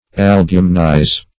albumenize - definition of albumenize - synonyms, pronunciation, spelling from Free Dictionary
Search Result for " albumenize" : The Collaborative International Dictionary of English v.0.48: Albumenize \Al*bu"men*ize\, v. t. [imp.